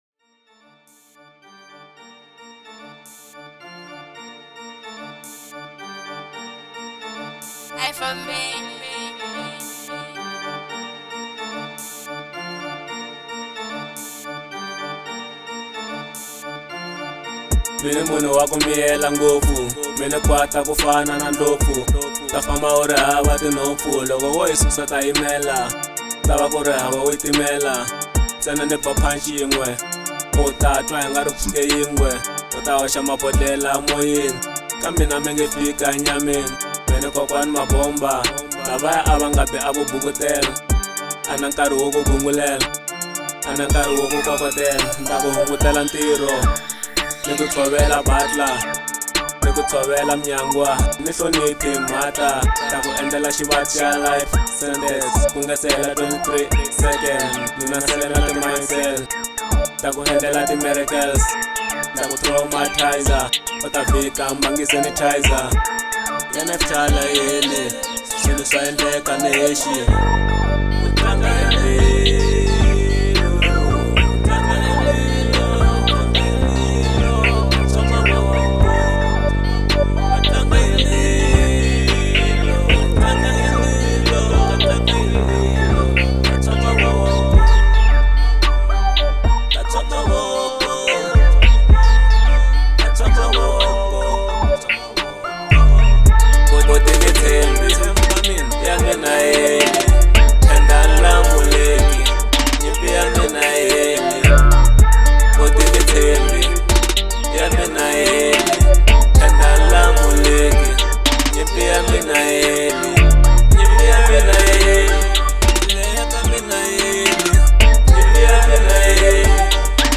04:13 Genre : Trap Size